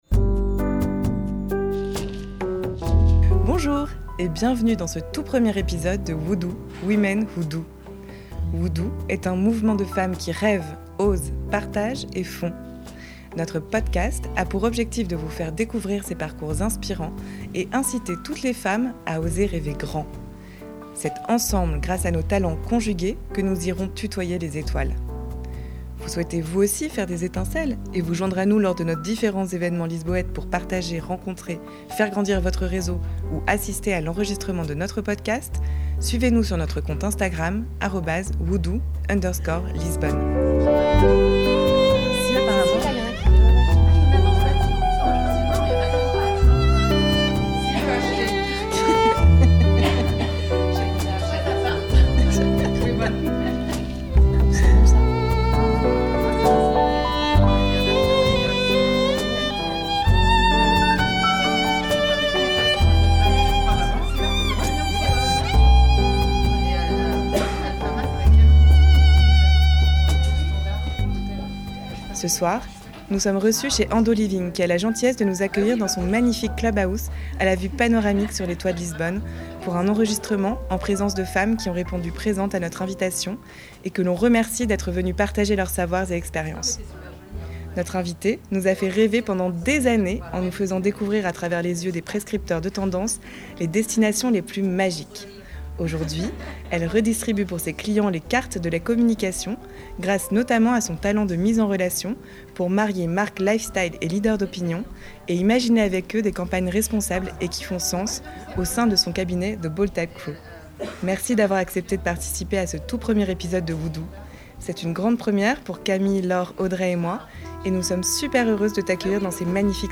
C’est lors du montage que ces digressions trouvent leur place, insufflant un rythme naturel à l’ensemble. Parallèlement, l’ajout d’un générique, d’une introduction et d’une conclusion journalistique vient structurer et encadrer l’entretien, tout en lui offrant une cohérence narrative.
Entretien : journaliste / interviewé
2- Début de l’entretien monté & mixé